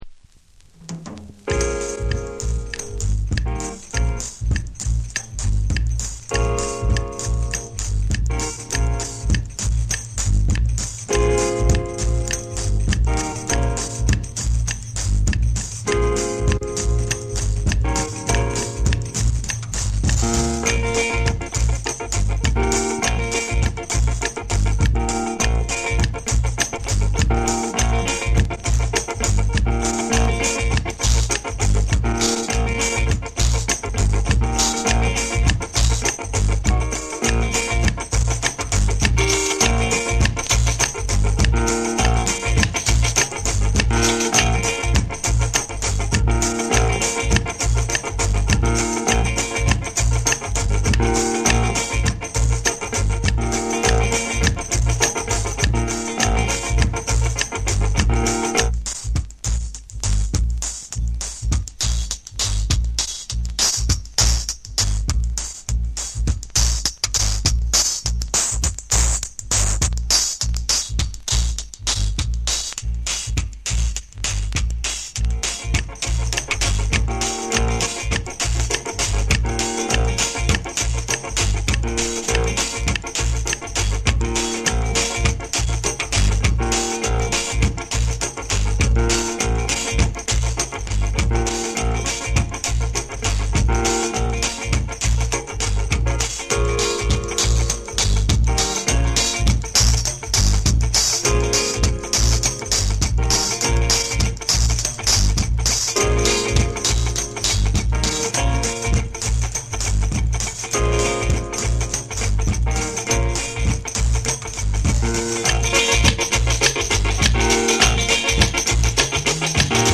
深く沈み込むベースラインと、エコー/リバーブを駆使した空間的なミックスが印象的ダブ・アルバム。
ジャマイカ盤特有のチリノイズが入ります。